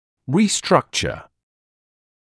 [ˌriː’strʌkʧə][ˌриː’стракчэ]реструктурировать; реорганизовывать